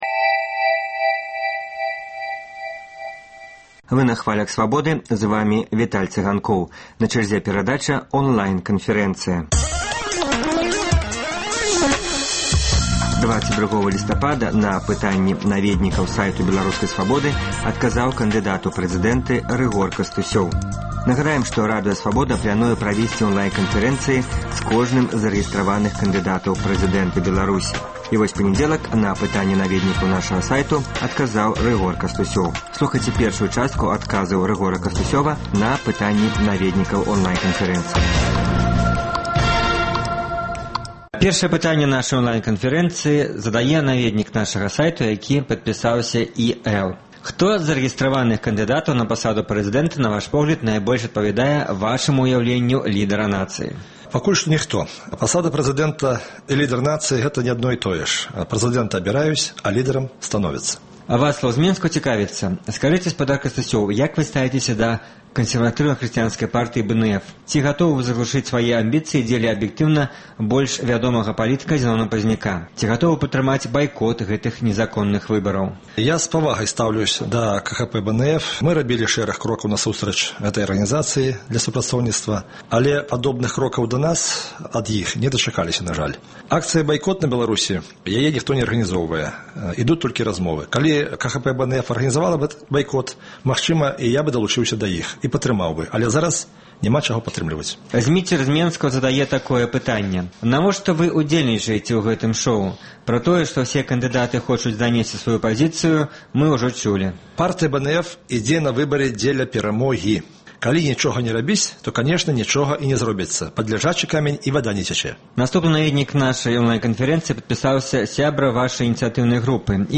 Першым адказаў на пытаньні наведнікаў сайту Свабоды кандыдат у прэзыдэнты, намесьнік старшыні Партыі БНФ Рыгор Кастусёў. Радыёварыянт онлайн-канфэрэнцыі.